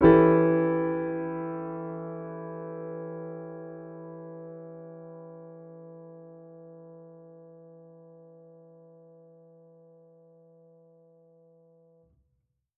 Index of /musicradar/jazz-keys-samples/Chord Hits/Acoustic Piano 1
JK_AcPiano1_Chord-Em13.wav